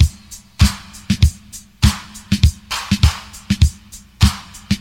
• 100 Bpm Drum Groove D Key.wav
Free breakbeat sample - kick tuned to the D note. Loudest frequency: 1167Hz
100-bpm-drum-groove-d-key-LV1.wav